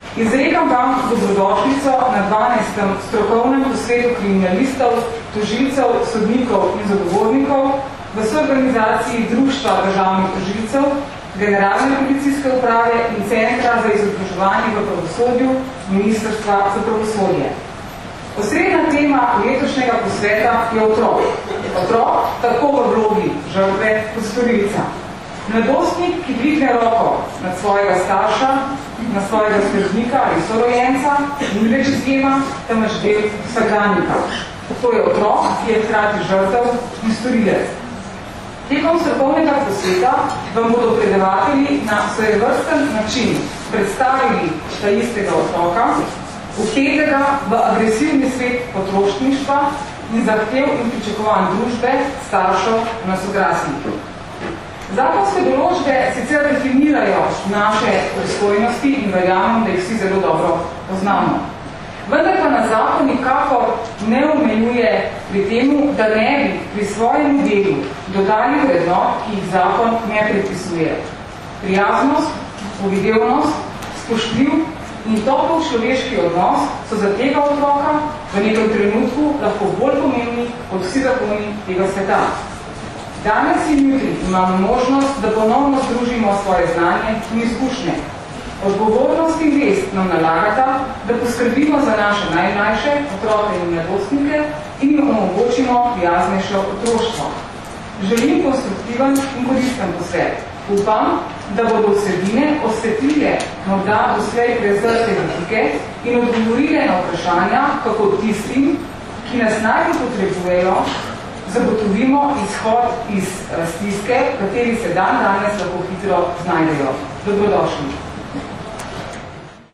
Na Brdu pri Kranju se je danes, 4. aprila 2013, začel dvodnevni strokovni posvet z naslovom Otrok v vlogi žrtve in storilca, ki ga že dvanajsto leto zapored organizirata Generalna policijska uprava in Društvo državnih tožilcev Slovenije v sodelovanju s Centrom za izobraževanje v pravosodju.
Zvočni posnetek uvodnega pozdrava mag. Tatjane Bobnar (velja govorjena beseda) (mp3)